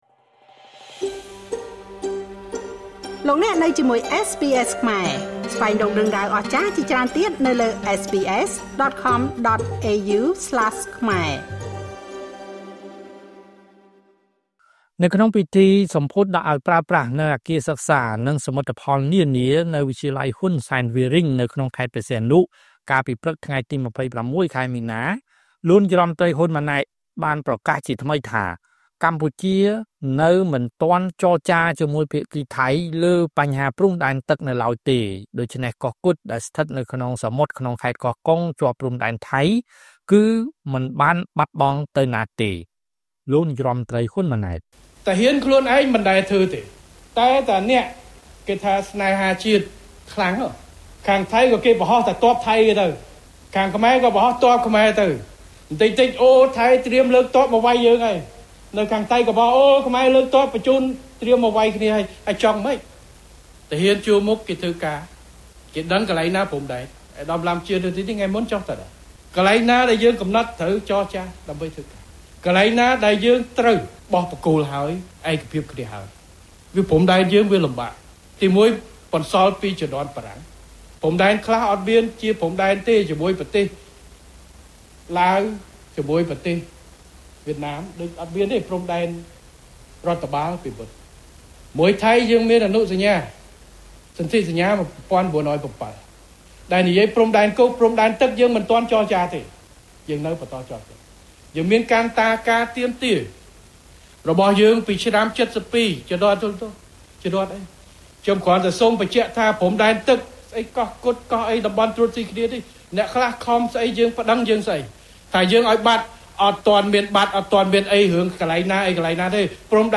ក្នុងពិធីសម្ពោធដាក់ឱ្យប្រើប្រាស់នូវអគារសិក្សា និងសមិទ្ធផលនានា នៅវិទ្យាល័យ ហ៊ុនសែន វាលរេញ ក្នុងខេត្តព្រះសីហនុ កាលពីព្រឹកថ្ងៃទី២៦ ខែមីនា ឆ្នាំ២០២៥ លោកនាយករដ្ឋមន្ត្រី ហ៊ុន ម៉ាណែត បានប្រកាសជាថ្មីថា កម្ពុជា នៅមិនទាន់ចរចាជាមួយភាគីថៃ លើព្រំដែនទឹក នៅឡើយទេ ដូច្នេះកោះគុត នៅក្នុងសមុទ្រខេត្តកោះកុង ជាប់ព្រំដែនថៃ គឺមិនបានបាត់បង់ទៅណាទេ។